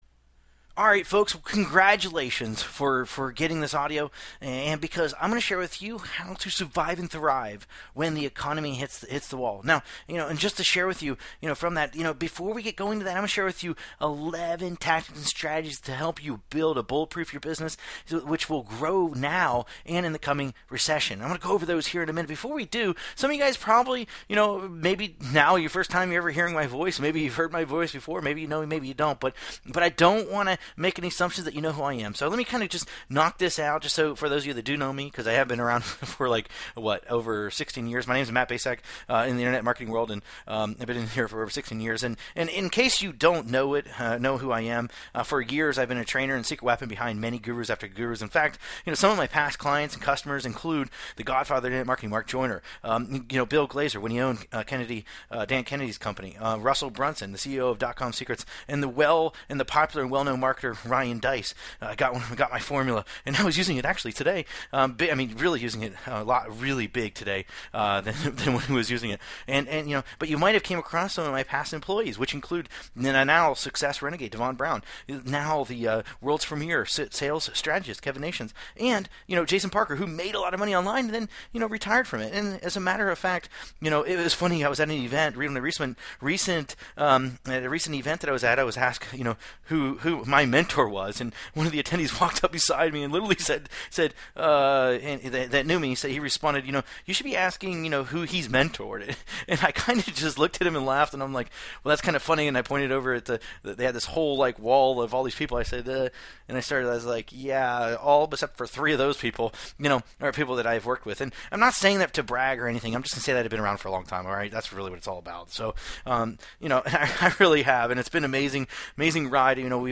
He talks a million miles a minute and changes direction mid-sentence about as many time. Also, I personally challenge anyone to count the number of times he says "y'know..."